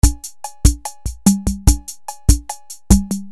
Drumcomputer
Der TR-808 folgte 1982 als preiswertere Preset-Variante die Roland CR-8000.
Roland CR-8000